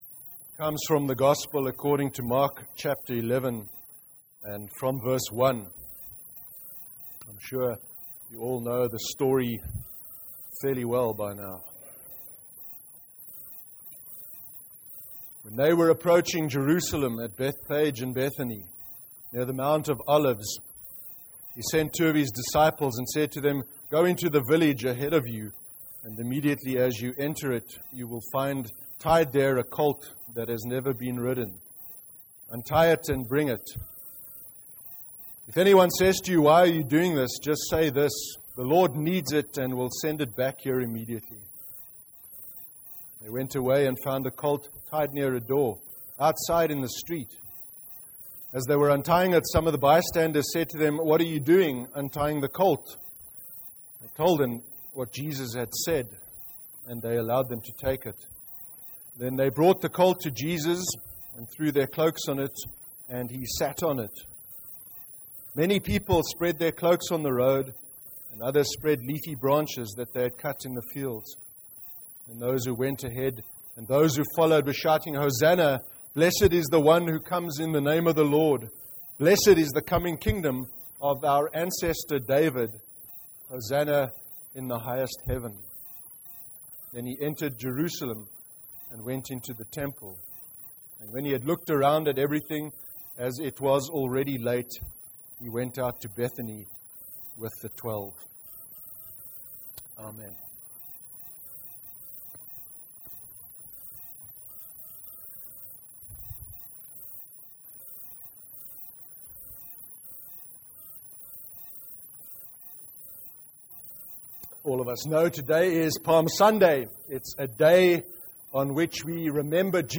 29/03/2015 sermon – Palm Sunday (Mark 11:1-11)